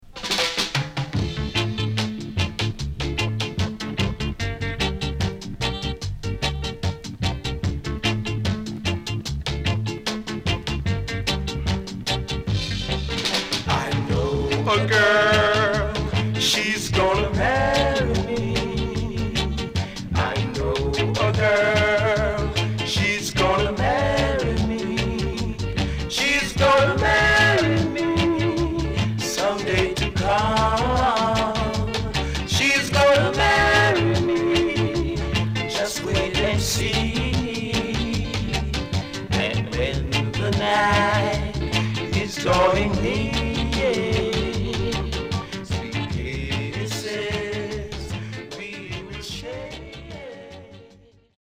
SIDE A:少しチリノイズ入りますが良好です。
SIDE B:少しチリノイズ入りますが良好です。